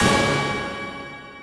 Boomin - Hit #05.wav